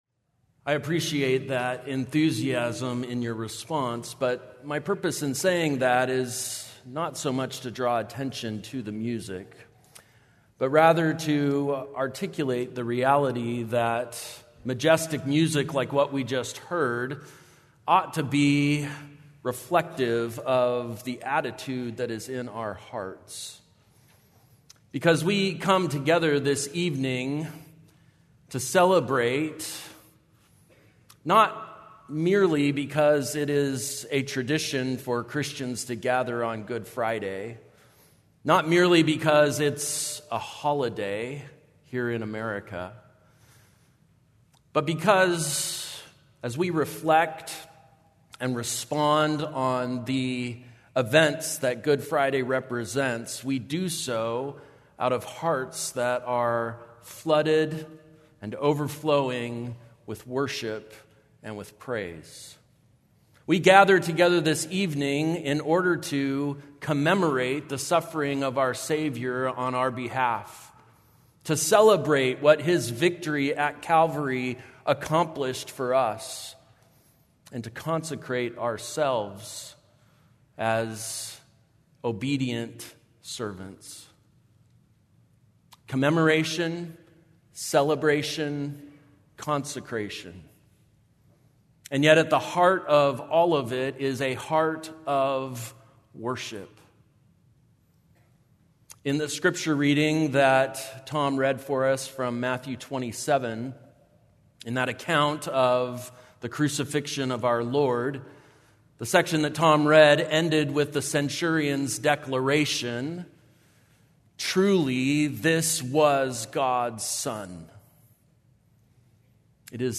April 18, 2025 - Good Friday Evening Sermon